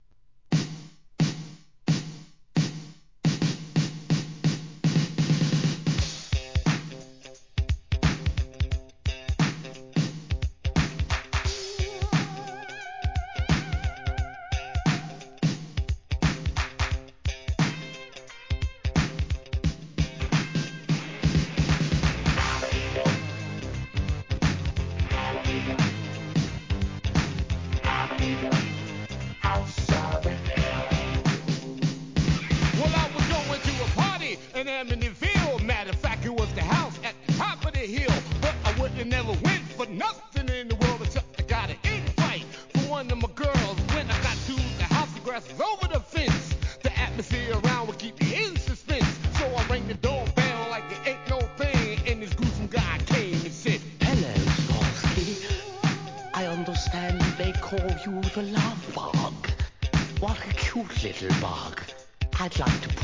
HIP HOP/R&B
OLD SCHOOL!!!!